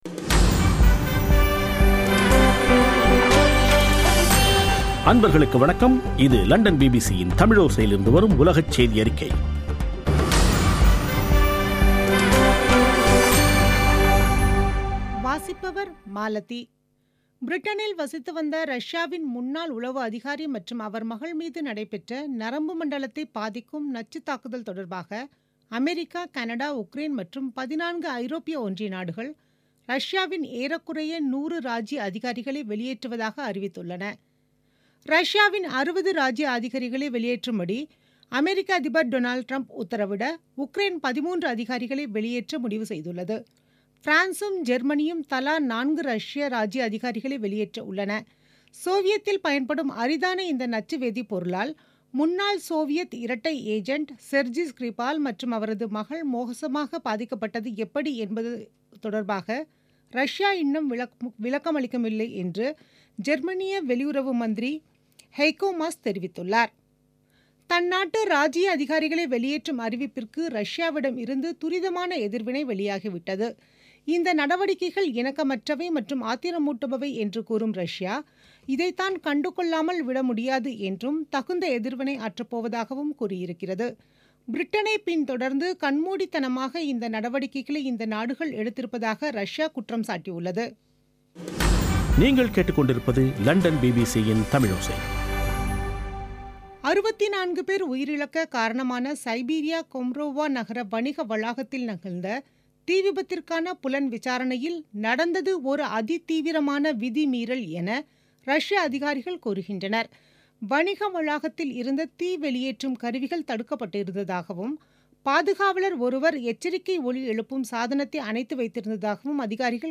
பிபிசி தமிழோசை செய்தியறிக்கை (26/03/2018)